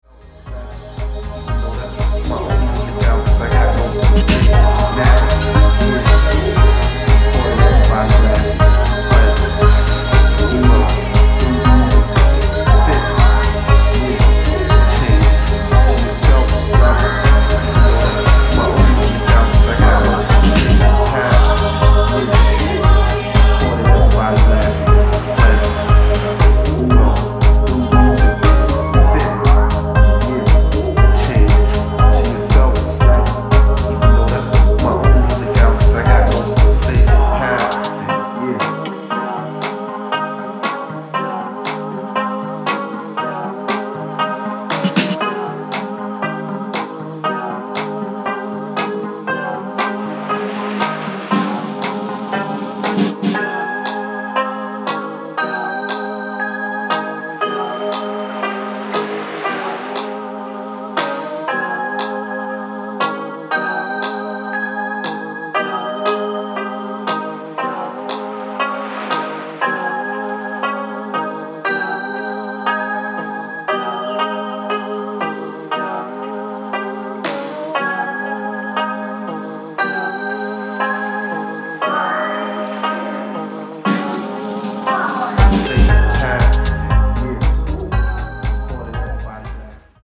沖縄民謡等のサンプルを重ね抑え気味のテンポで展開する情緒豊かなミニマル・ハウス